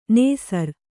♪ nēsar